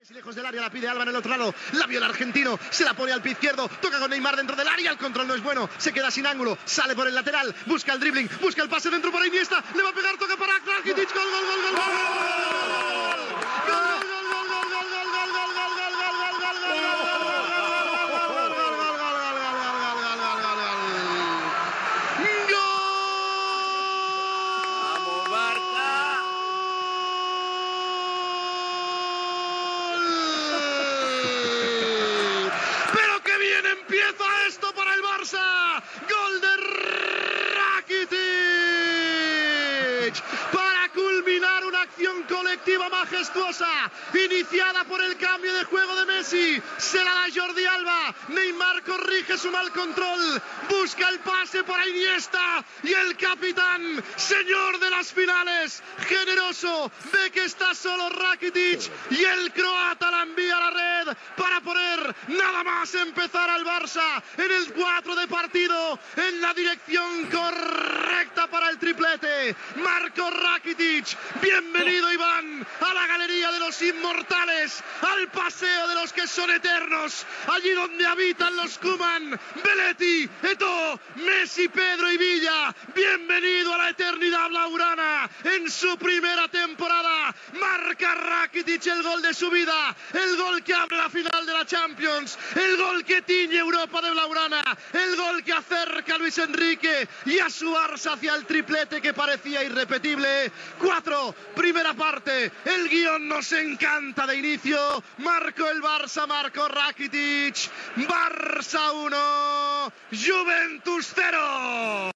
Transmissió de la final de la Copa d'Europa de futbol masculí, des de l'Olympiastadion de Berlín, del partit entre el Futbol Club Barcelona i la Juventus.
Narració del gol de Rakitić.
Esportiu